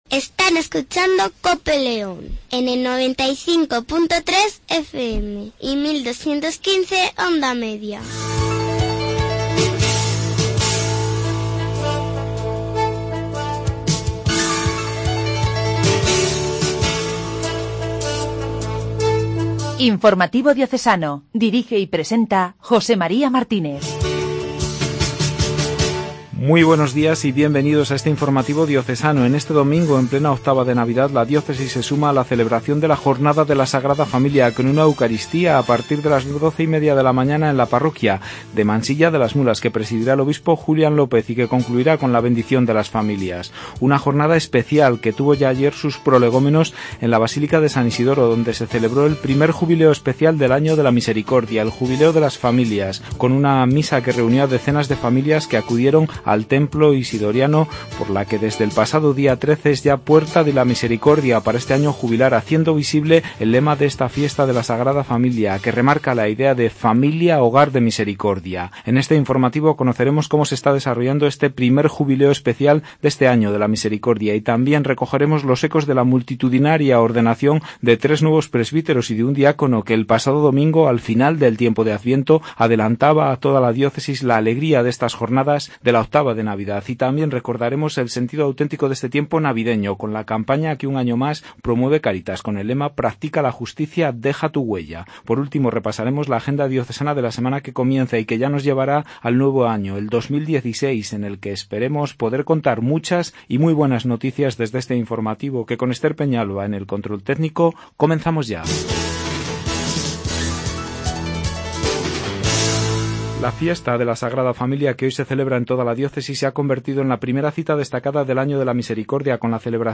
INFORMATIVO DIOCESANO